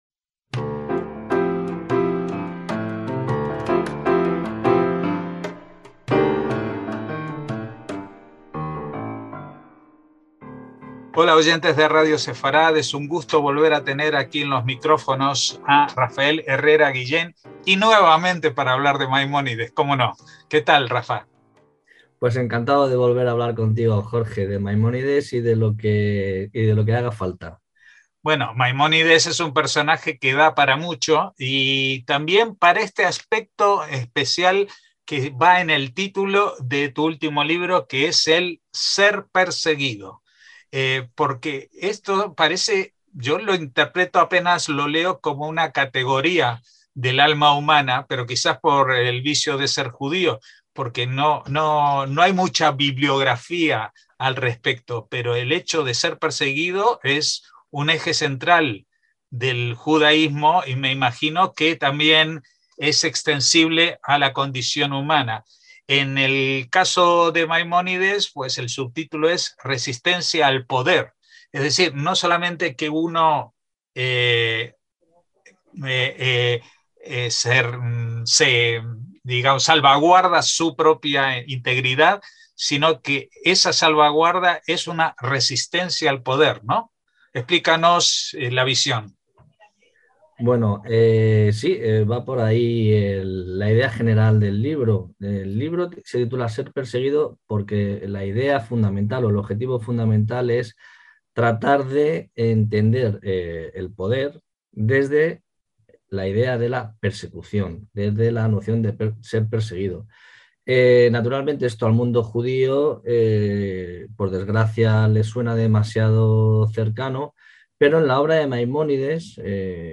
Para explicarlo ha editado este nuevo libro Ser perseguido: resistencia al poder en Maimónides (Ed. Tecnos) que recomendamos junto con la audición de sus propias palabras en esta entrevista.